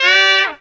HARM RIFF 1.wav